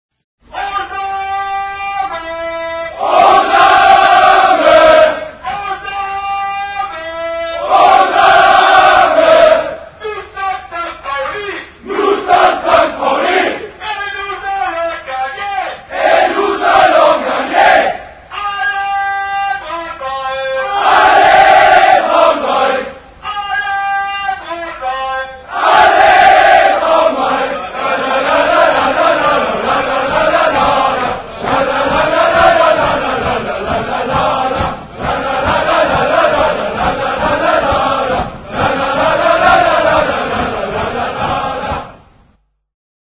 Audio Sankt Pauli Fans stimmen ein: Nous sommes St. Pauli